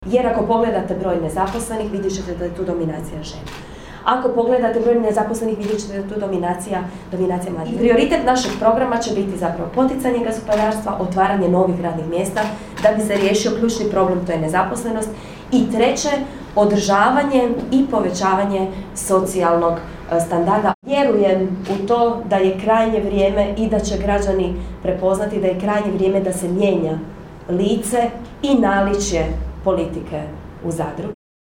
Svim prisutnima obratila se i Sabina Glasovac, buduća zamjenica gradonačelnika koja će biti u obvezi provesti rodnu politiku na lokalnoj razini.